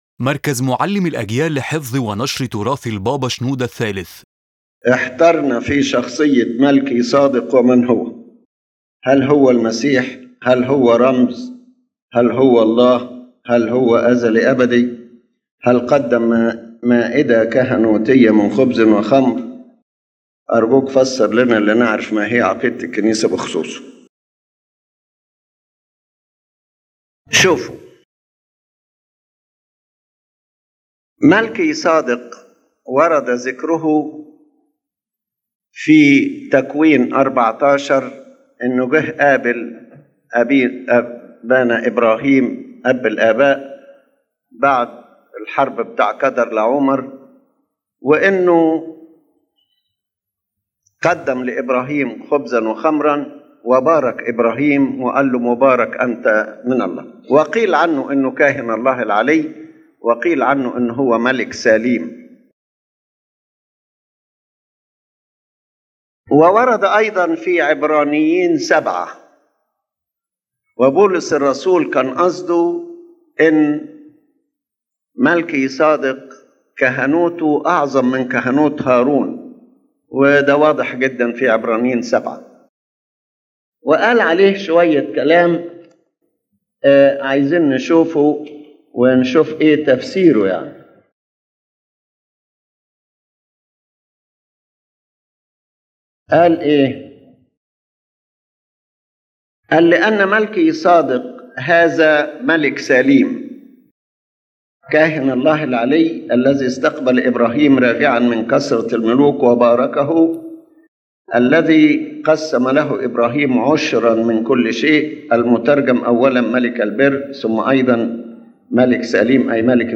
In this lecture His Holiness Pope Shenouda explains the Coptic Orthodox Church’s stance on the figure Melchizedek as mentioned in Genesis and the Epistle to the Hebrews. He affirms that Melchizedek is a real historical person — a king and priest of the city Salem — and that his description in the Old Testament is used symbolically to demonstrate the greater priesthood of Christ without making him an incarnation of the Lord Himself.